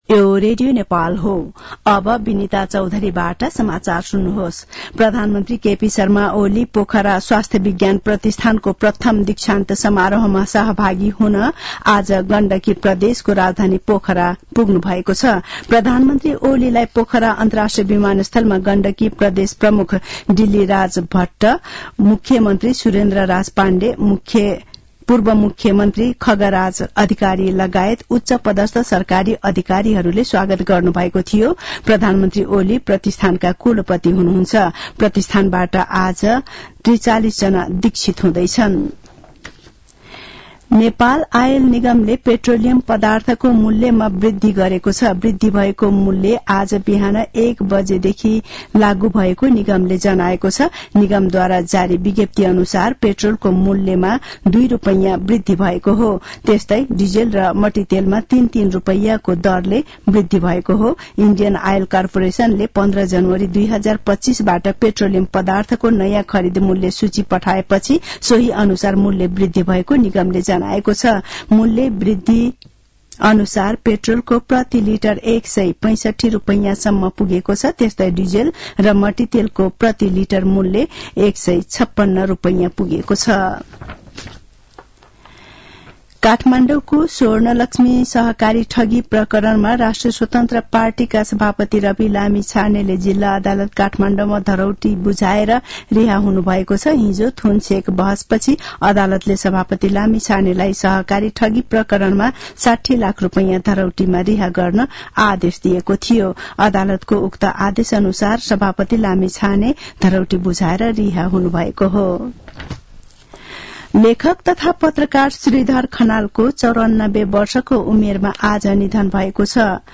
दिउँसो १ बजेको नेपाली समाचार : ४ माघ , २०८१